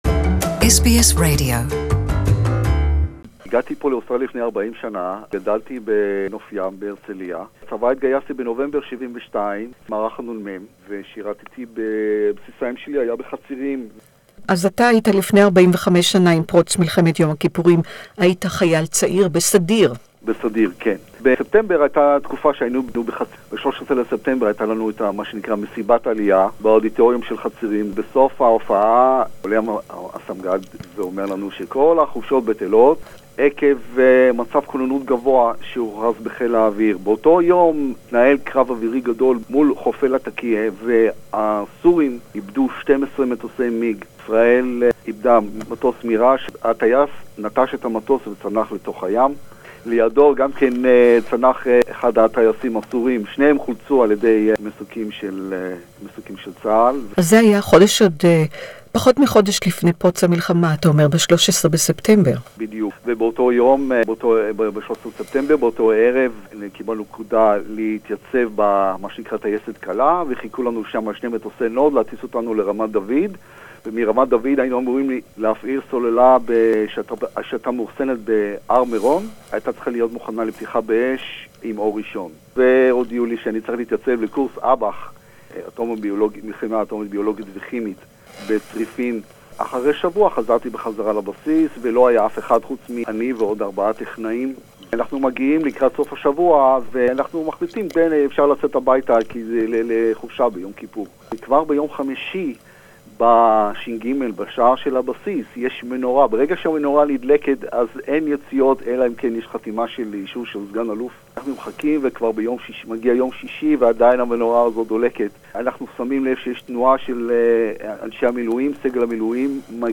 Hebrew Interview